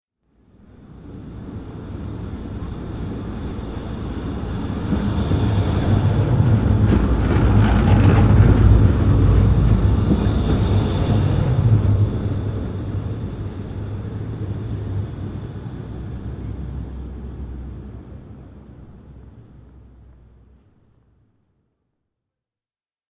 دانلود آهنگ قطار 16 از افکت صوتی حمل و نقل
جلوه های صوتی
دانلود صدای قطار 16 از ساعد نیوز با لینک مستقیم و کیفیت بالا